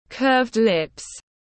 Môi cong tiếng anh gọi là curved lips, phiên âm tiếng anh đọc là /kɜːvd lɪp/ .